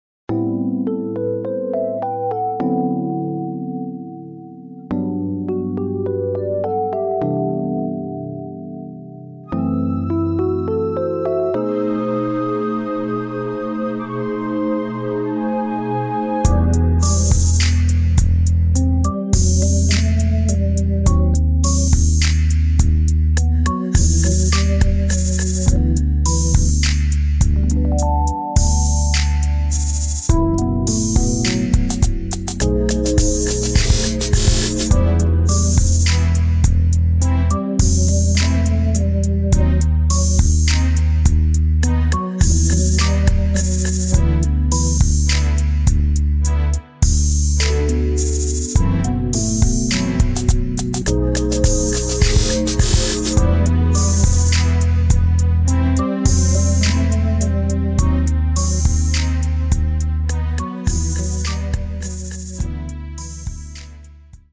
Keyboards
Guitar, Bass